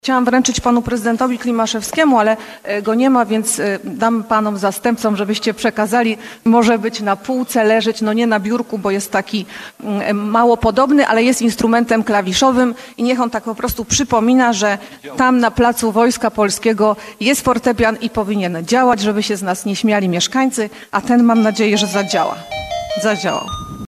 Bielsko-Biała: w trakcie dzisiejszej sesji miejskiej powrócił temat awarii fortepianu ustawionego na Pl. Wojska Polskiego.